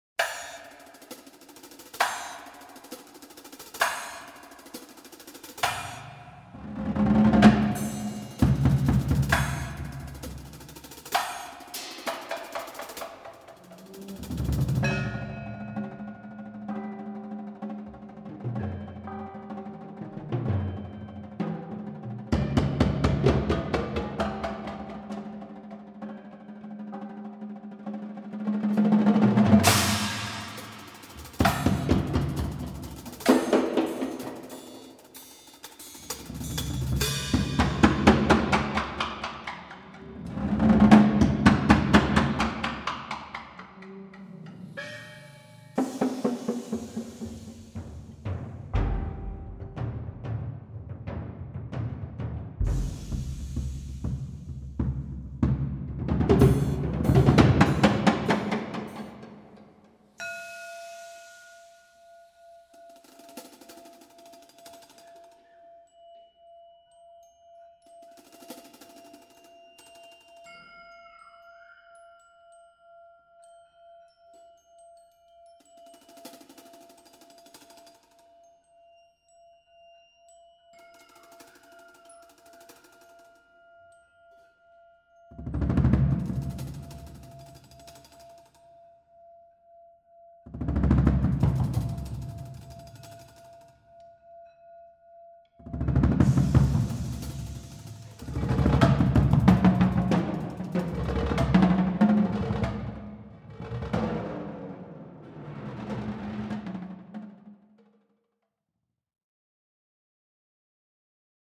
Voicing: Percussion Quintet